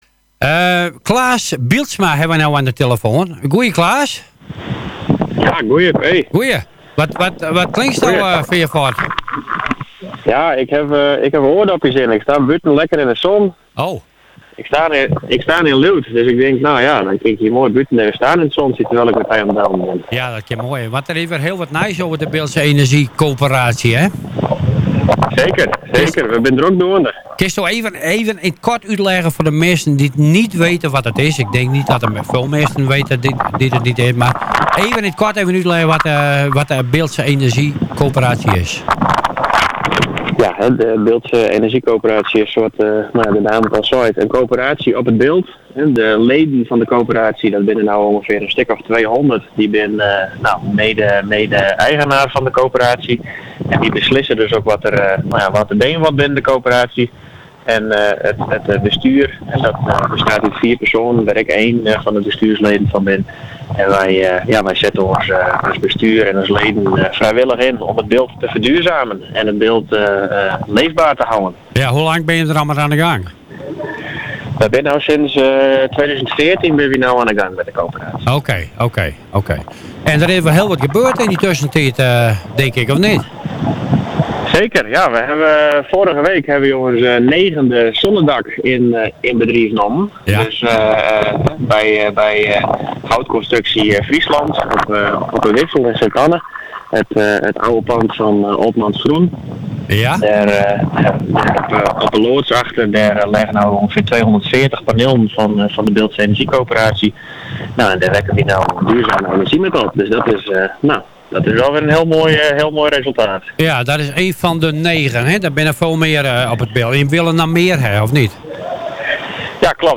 Interview bij Radio Eenhoorn - Bildtse Energie Coöperatie